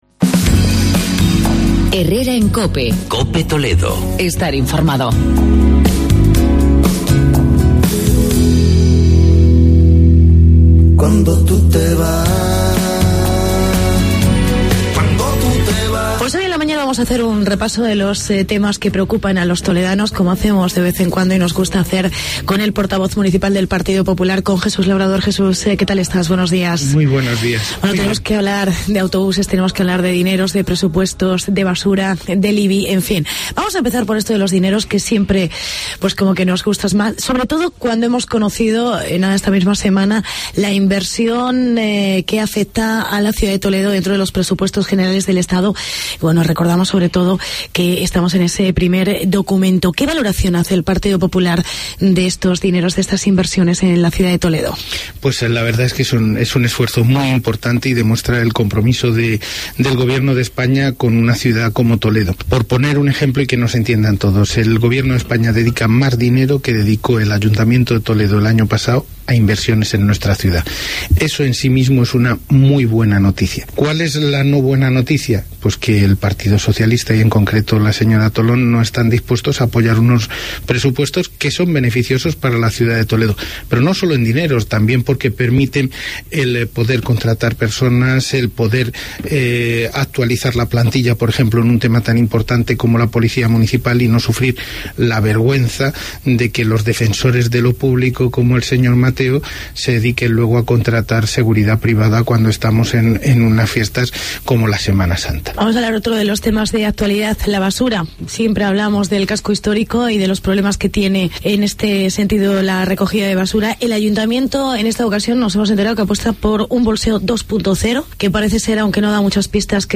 ENTREVISTA CON JESUS LABRADOR. Portavoz del PP en Toledo